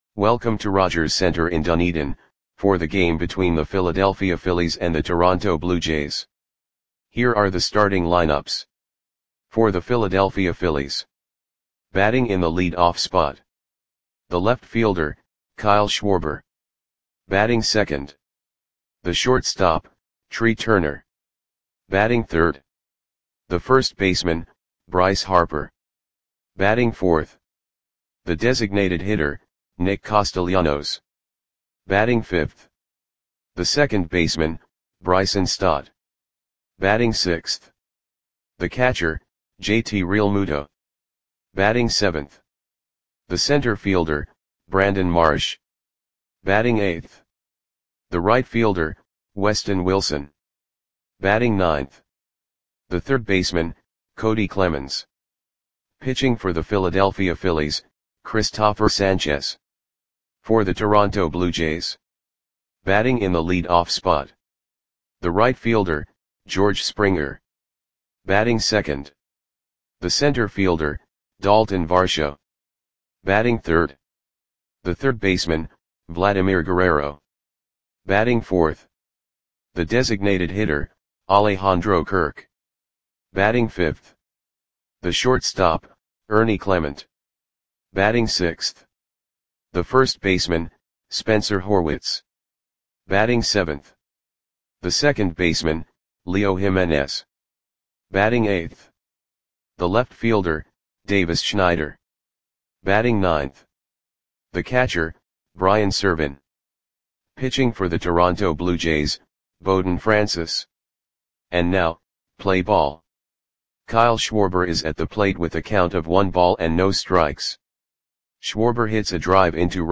Audio Play-by-Play for Toronto Blue Jays on September 4, 2024
Click the button below to listen to the audio play-by-play.